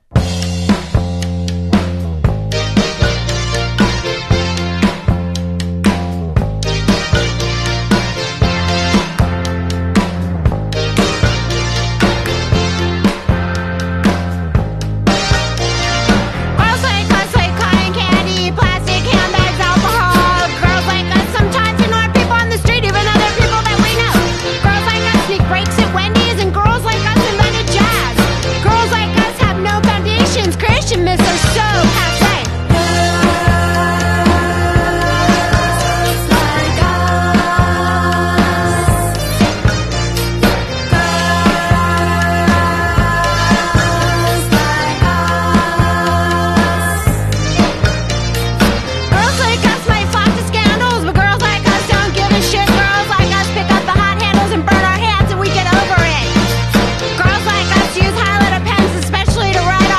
feminist punk music